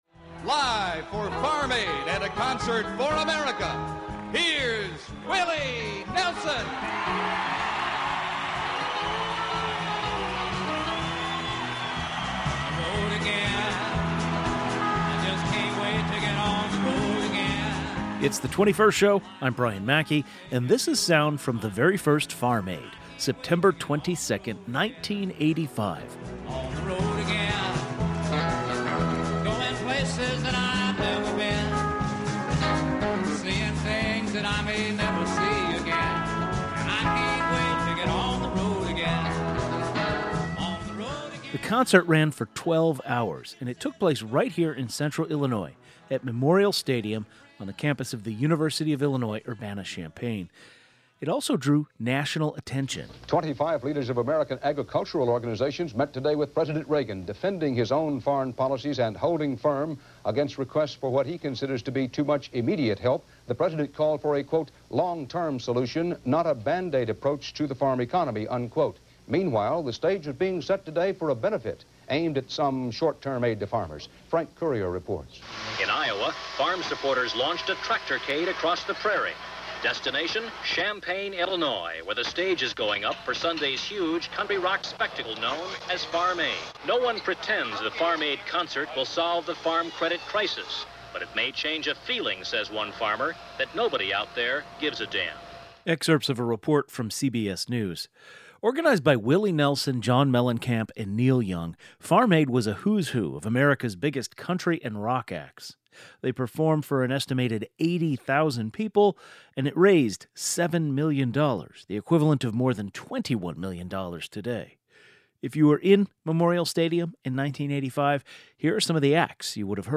The 21st Show is Illinois' statewide weekday public radio talk show, connecting Illinois and bringing you the news, culture, and stories that matter to the 21st state.
Today's show included a rebroadcast of the following "best of" segment, first aired September 23, 2020: Farm Aid 35th Anniversary.